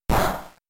hit-weak-not-very-effective.mp3